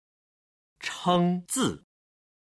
今日の振り返り無料中国語音源